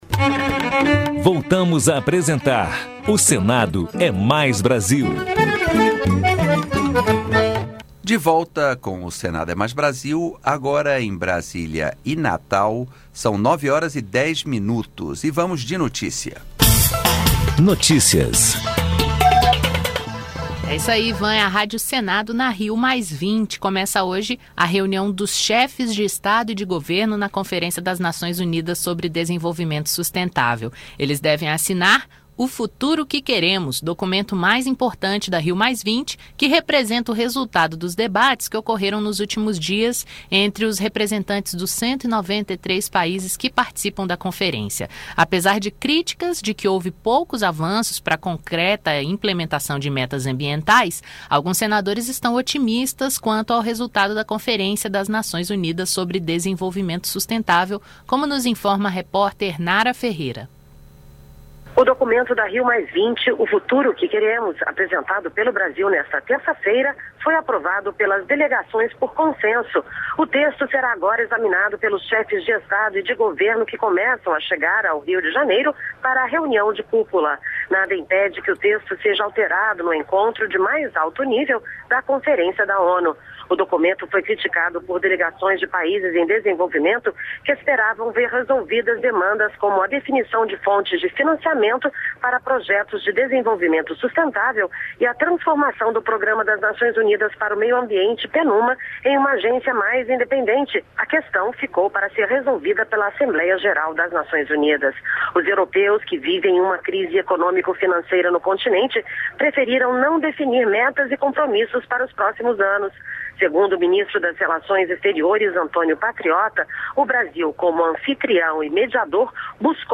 Entrevistas regionais, notícias e informações sobre o Senado Federal